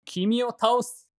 戦闘 バトル ボイス 声素材 – Battle Voice